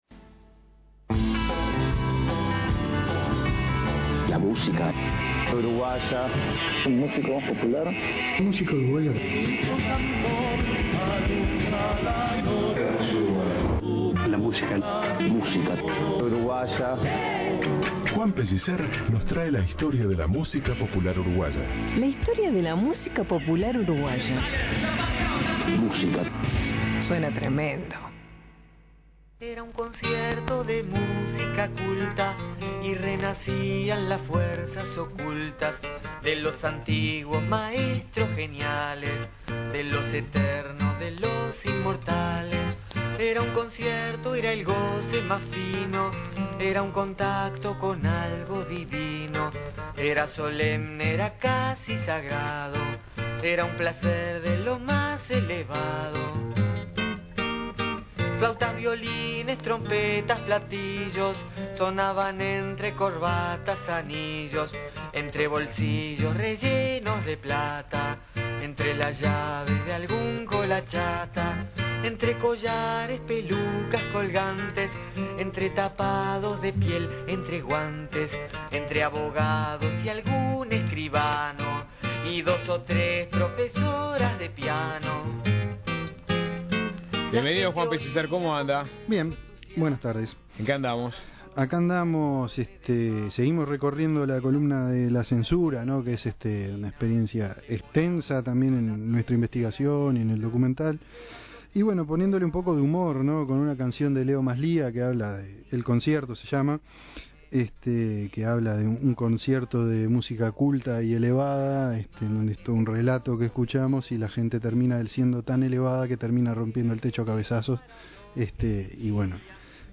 testimonios de los músicos